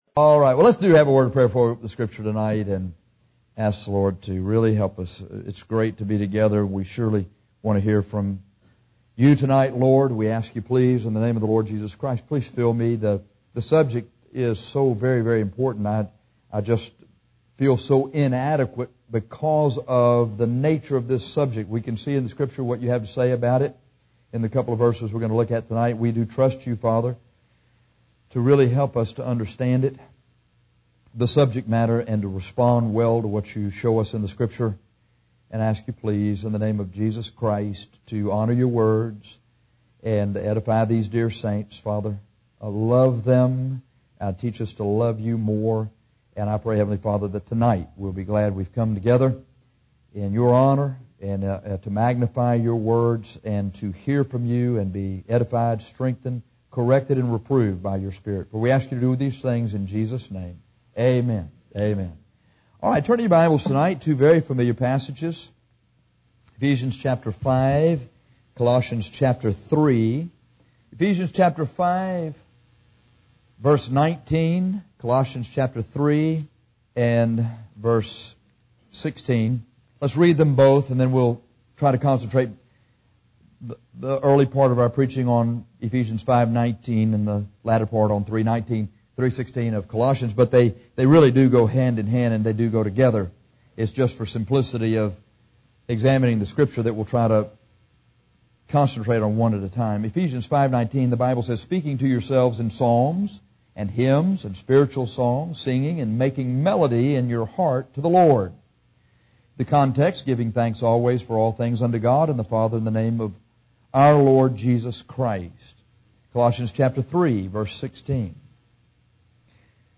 The purpose of congregational singing from a hymnbook in a fellowship of believers is the subject of this sermon.